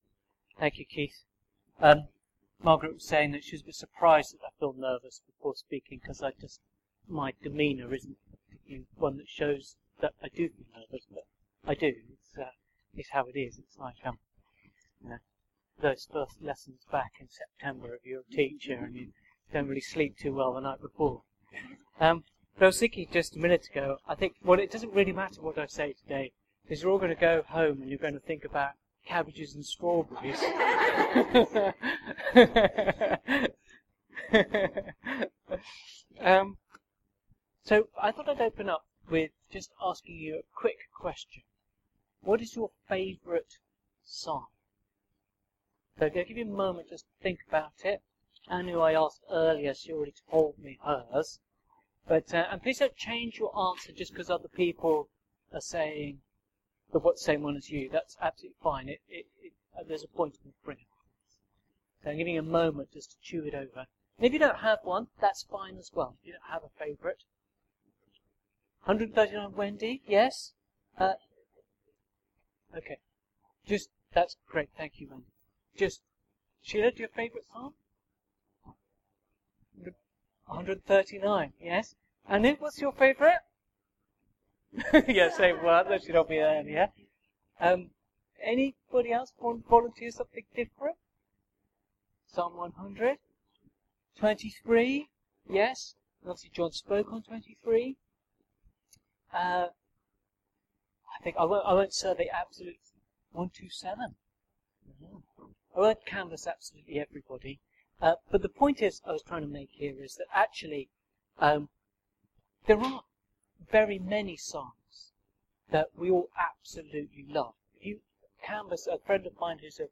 Categories Sermon Podcasts